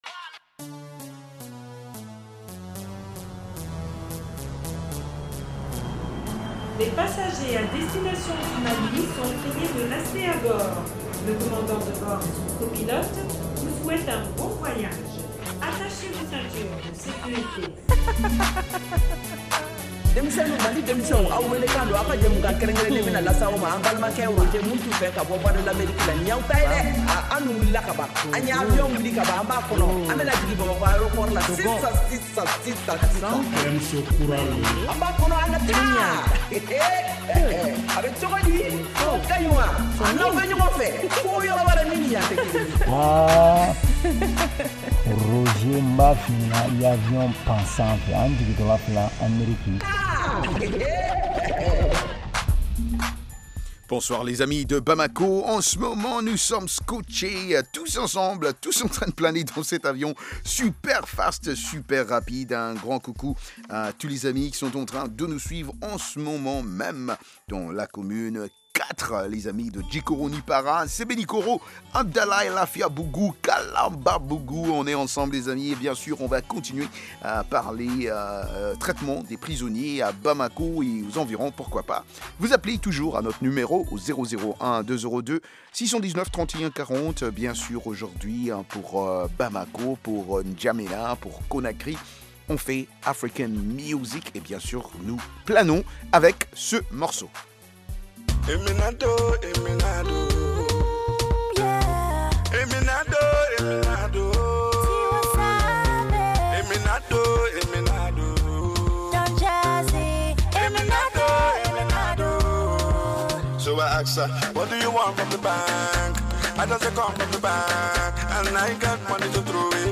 Emission quotidienne de musique et d’entretien avec les auditeurs.